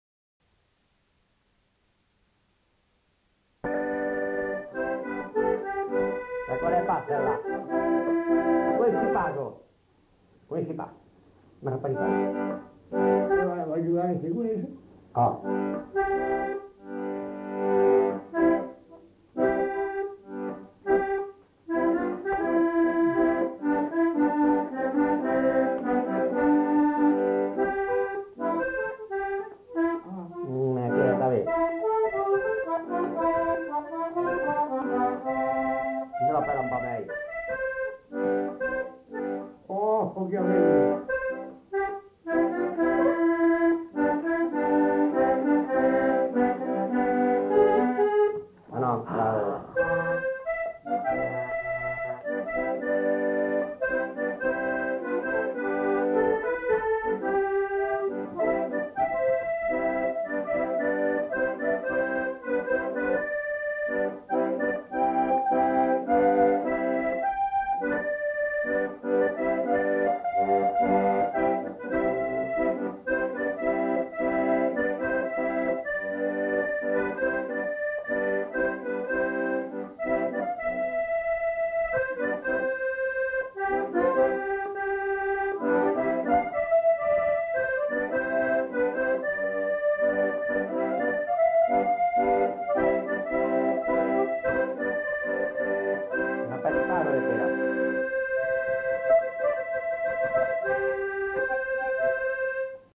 Répertoire de danses des Lugues
à l'accordéon diatonique
enquêtes sonores